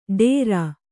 ♪ ḍērā